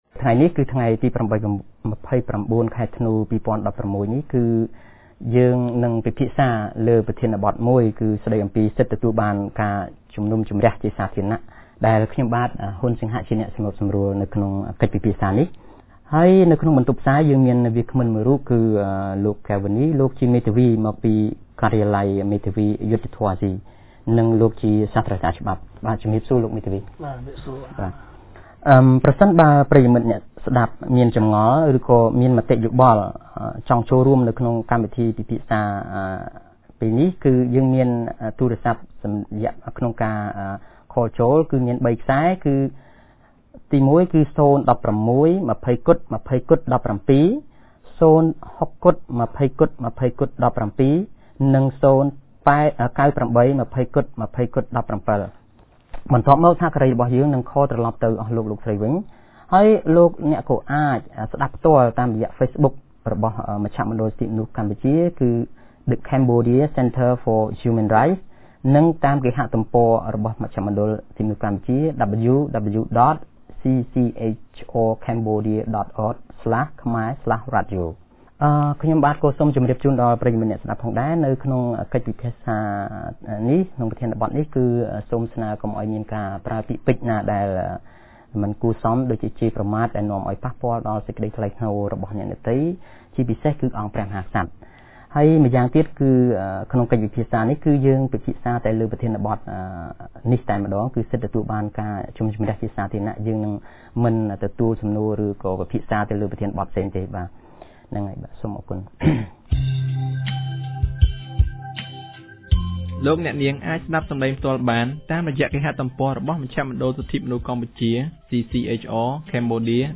On 29 December 2016, CCHR Fair Trial Rights Project held a radio program with a topic on “Right to Public Hearing".